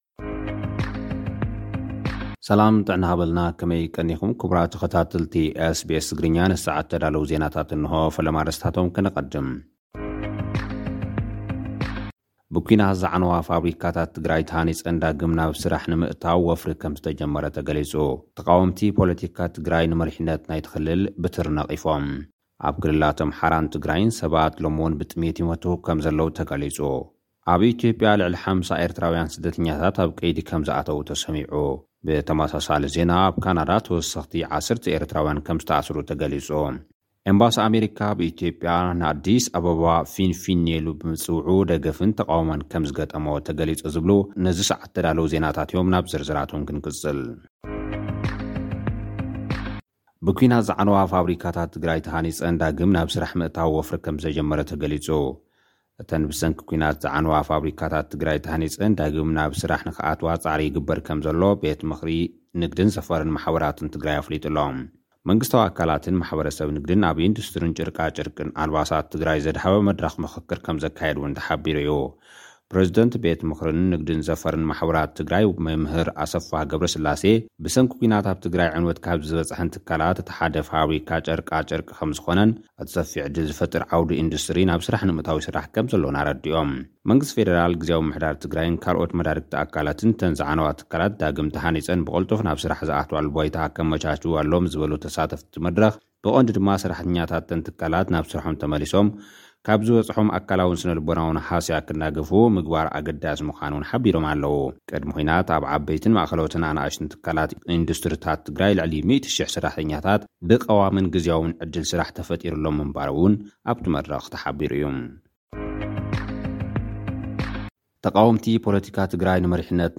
ልኡኽና ዝሰደደልና ጸብጻብ እዞም ዝስዕቡ ኣርእስታት ዝሓዘ እዩ። ብኲናት ዝዓነዋ ፋብሪካታት ትግራይ ተሃኒፀን ዳግም ናብ ስራሕ ምእታዉ ወፍሪ ከም ዝተጀመረ ተገሊጹ። ተቓወምቲ ፖለቲካ ትግራይ ንመሪሕነት ናይቲ ክልል ነቒፎም።